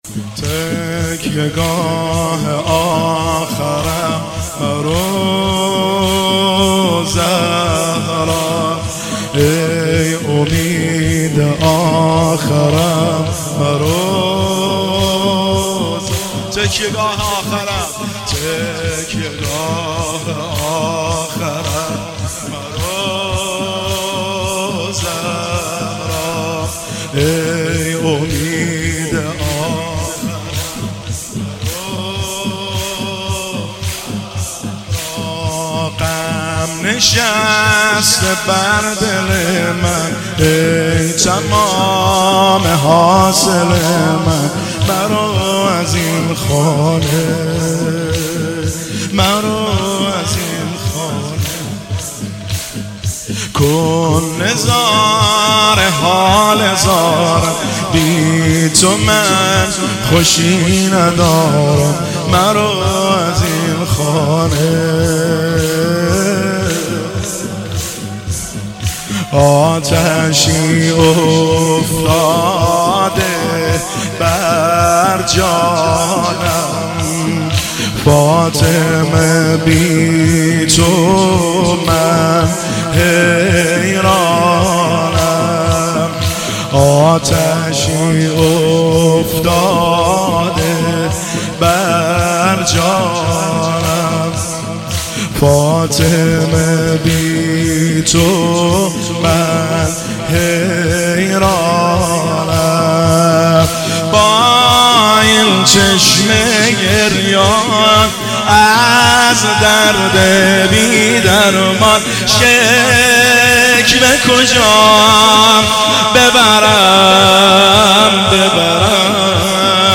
تکیه گاه آخرم مرو زهرا زمینه – شب اول فاطمیه دوم 1402 هیئت بین الحرمین طهران